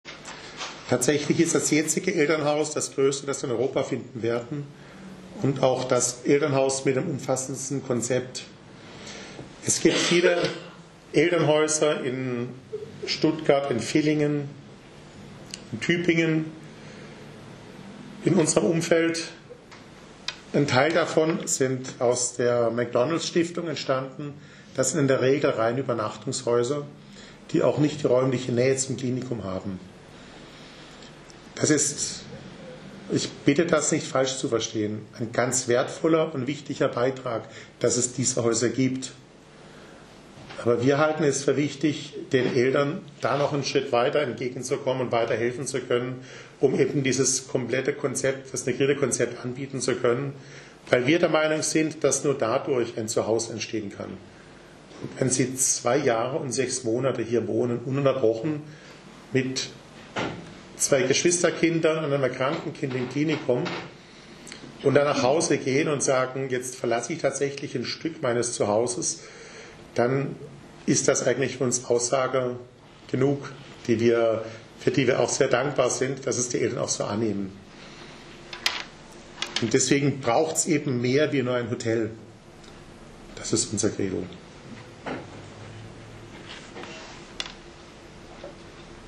Pressekonferenz "Neubau Elternhaus"